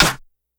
Snares
snr_02.wav